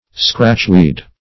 Scratchweed \Scratch"weed`\, n. (Bot.) Cleavers.